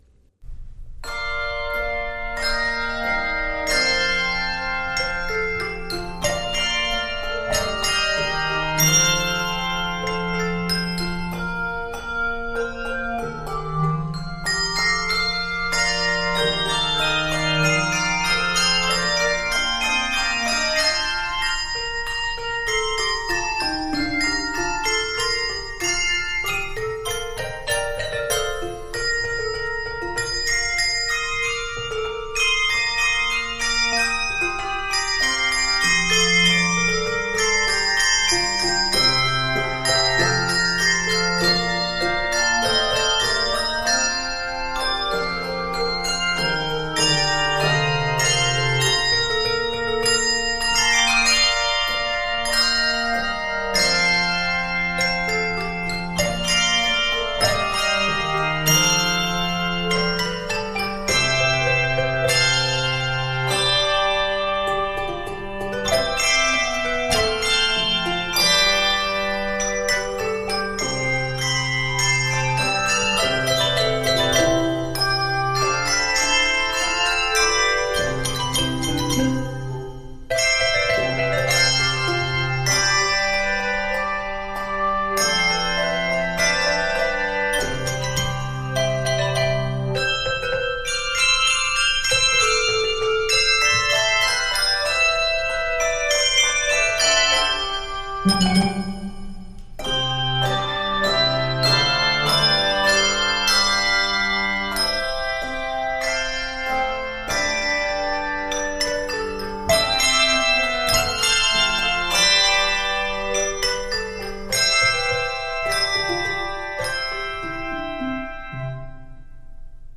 Keys of Bb Major and C Major.
Octaves: 3-5